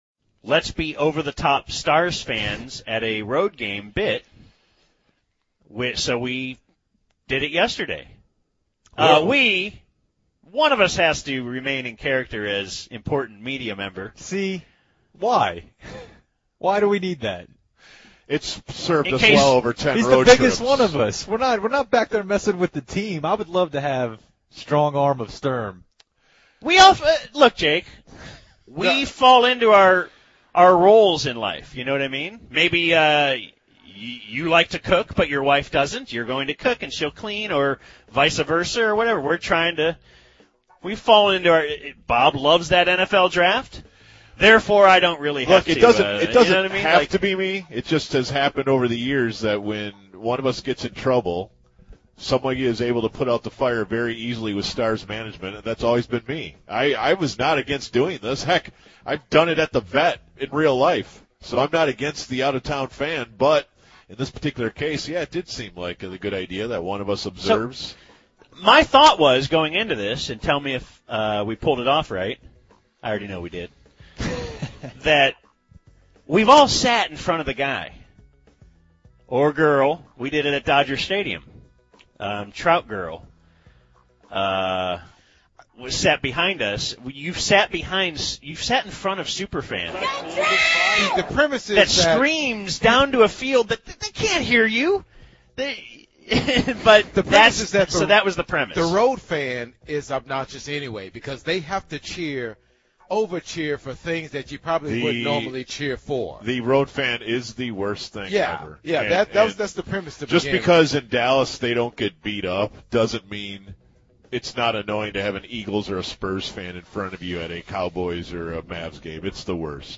While on their Stars road trip, Bad Radio tries to piss off the fans at MSG by excessively over cheering.